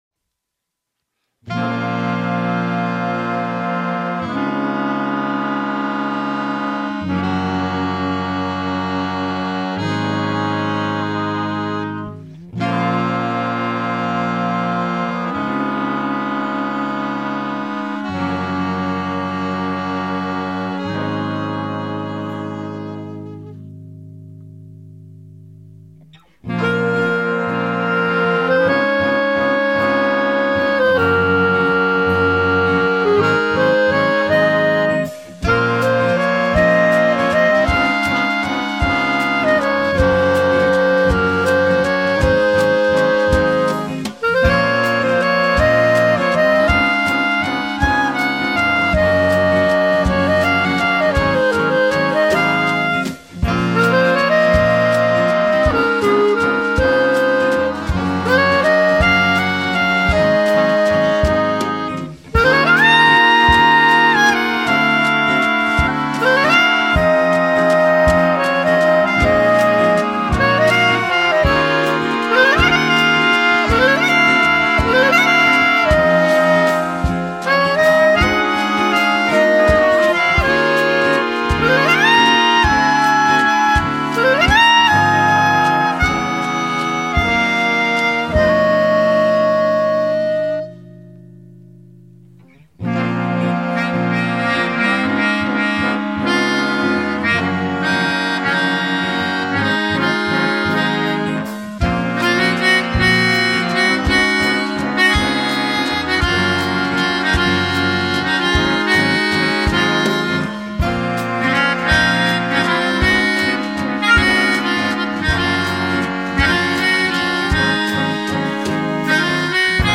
Trumpet, Trombone and Bass
Saxophone
Clarinet, Guitar and Melodica
and all solos were improvs.